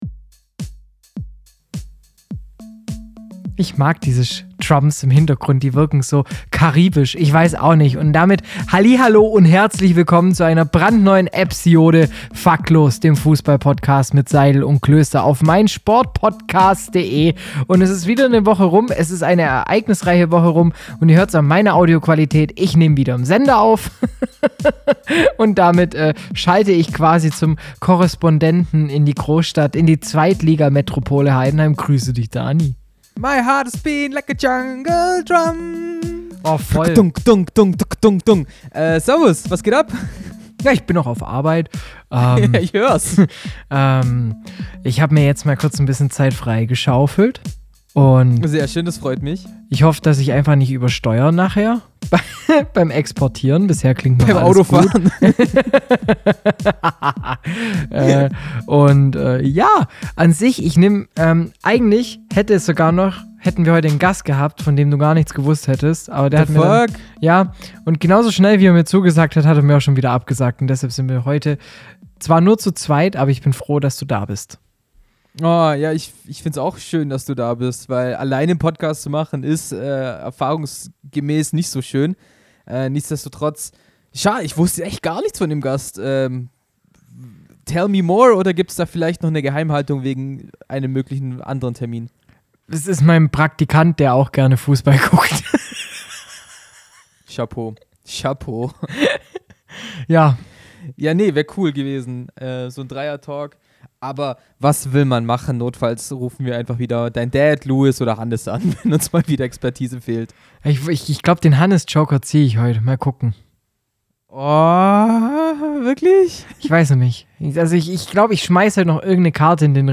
Zur nächsten Fahrraddemo in Ulm haben wir mit den Organisatoren gesprochen. Gleichzeitig erwartet euch ein Interview über nachhaltiges Leben in der Stadt mit dem Agenda Büro der Stadt Ulm.